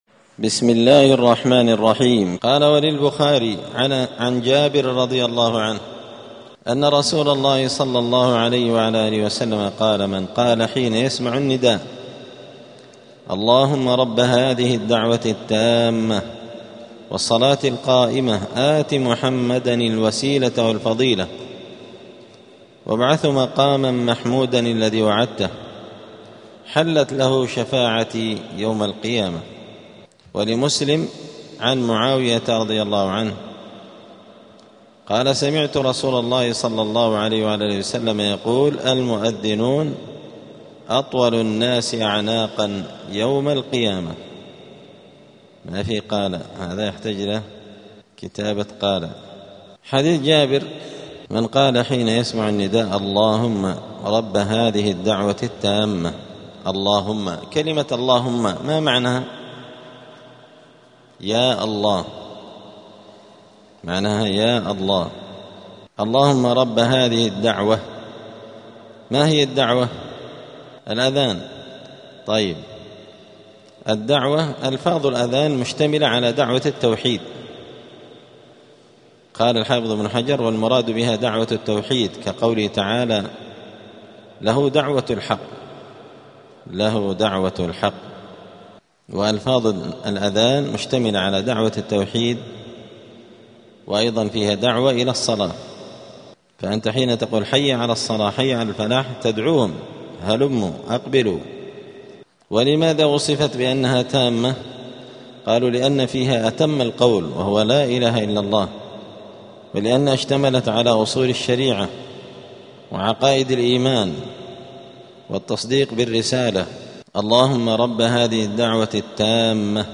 دار الحديث السلفية بمسجد الفرقان قشن المهرة اليمن
*الدرس الخامس والخمسون بعد المائة [155] باب الأذان {هل يردد وراء المؤذن من لم يكن على طهارة}*